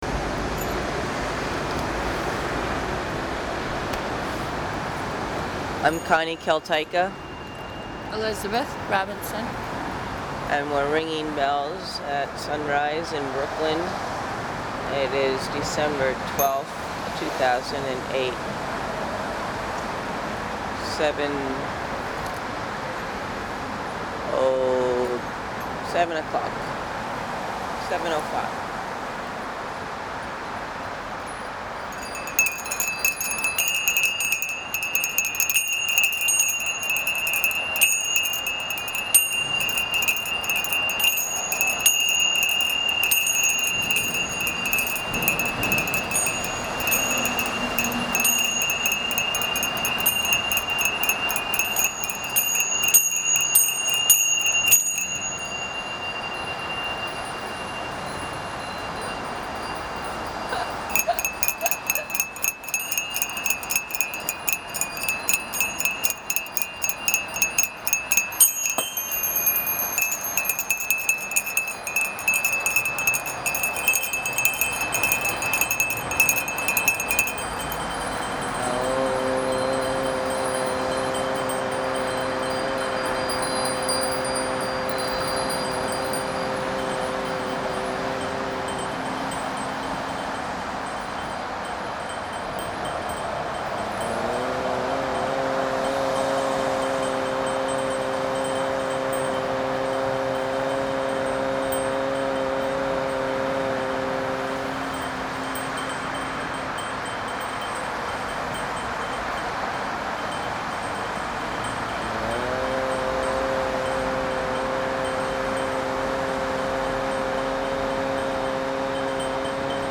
ringing bells and singing by the bridge at 7:05AM